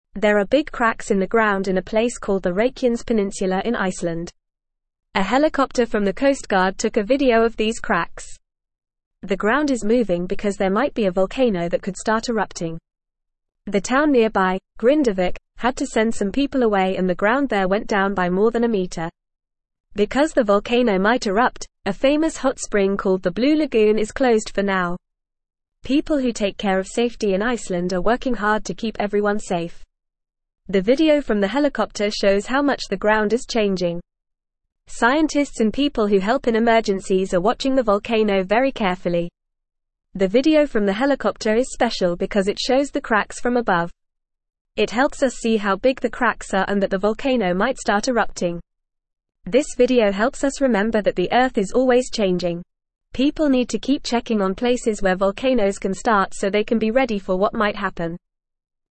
Fast
English-Newsroom-Lower-Intermediate-FAST-Reading-Cracks-in-Ground-Volcano-Waking-Up-in-Iceland.mp3